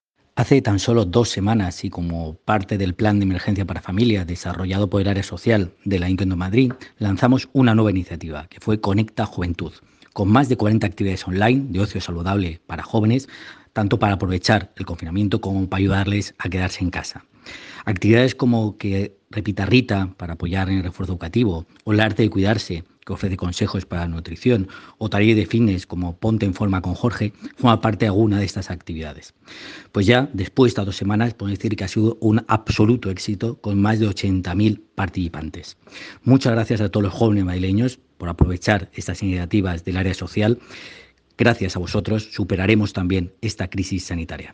Nueva ventana:Declaraciones del delegado Pepe Aniorte sobre el Proyecto online para jóvenes Conecta Juventud 2.0